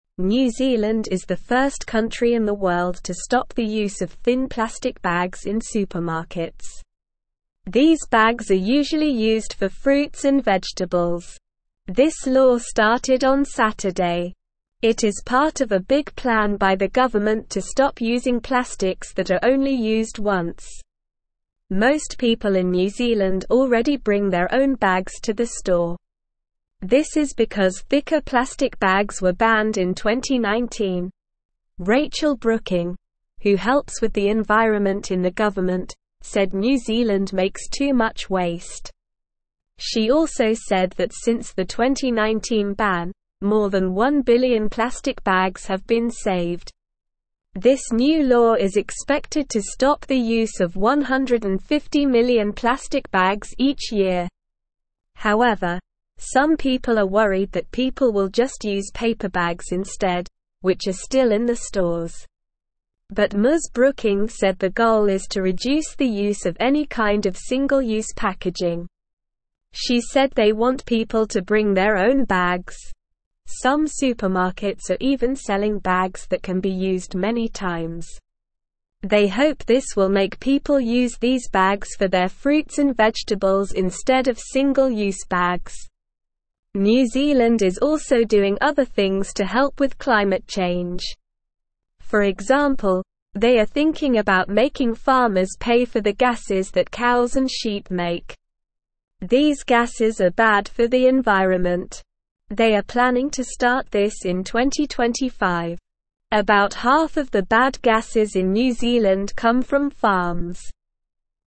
Slow
English-Newsroom-Beginner-SLOW-Reading-New-Zealand-Stops-Using-Thin-Plastic-Bags.mp3